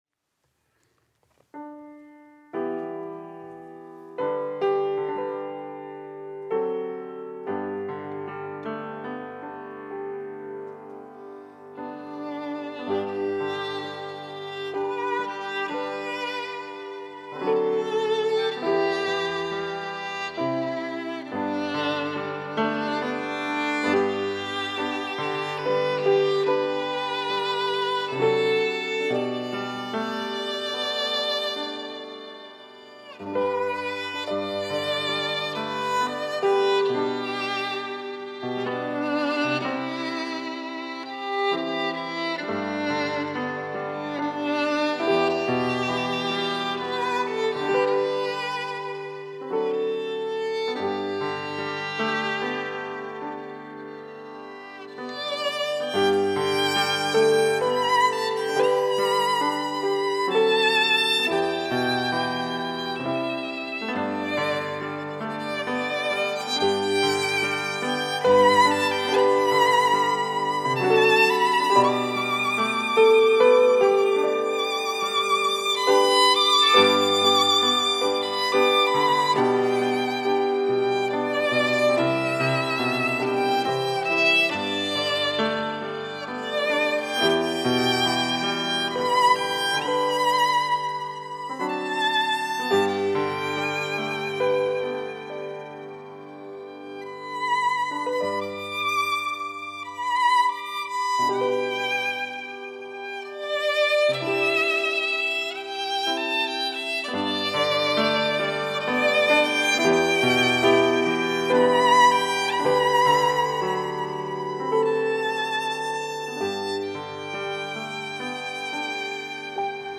특송과 특주 - 나 같은 죄인 살리신